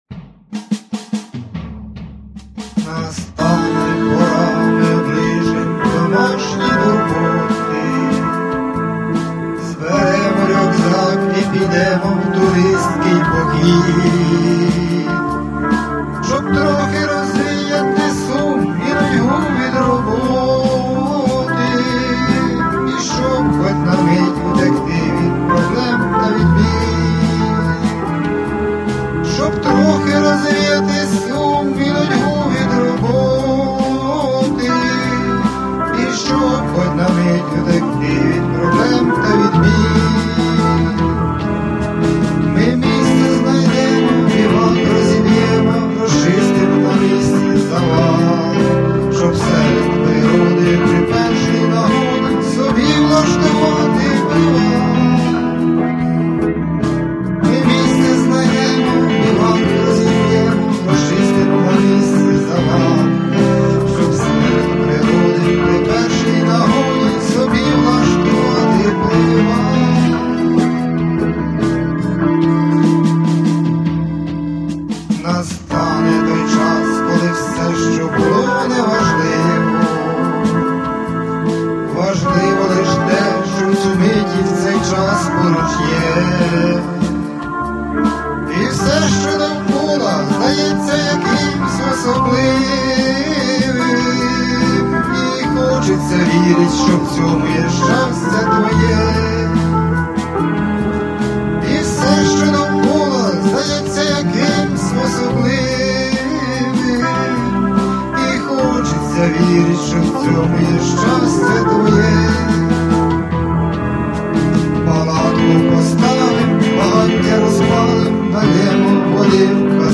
Пісня туристична, трішки ностальгічно-лірична
Рубрика: Поезія, Авторська пісня